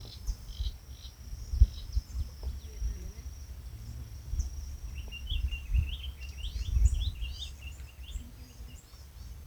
Frutero Azul (Stephanophorus diadematus)
Nombre en inglés: Diademed Tanager
Localidad o área protegida: Colonia Las Margaritas
Condición: Silvestre
Certeza: Fotografiada, Vocalización Grabada
Frutero-azul.mp3